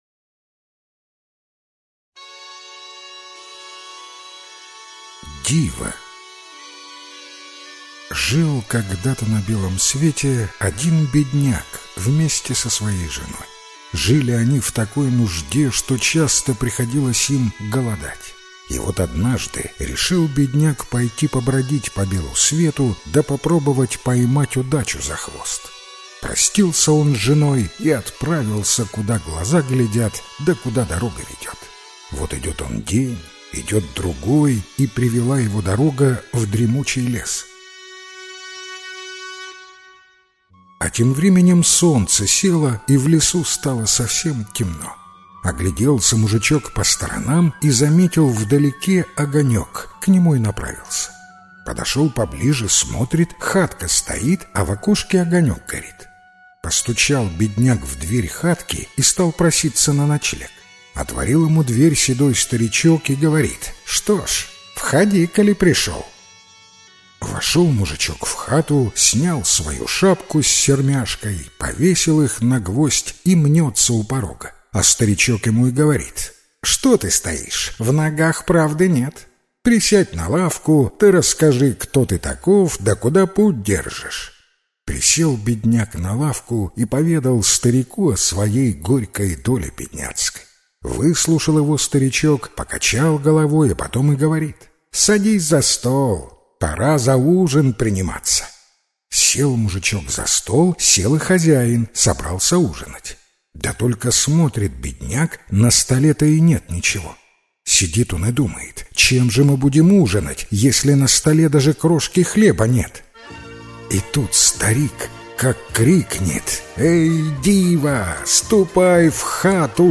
Диво - белорусская аудиосказка - слушать онлайн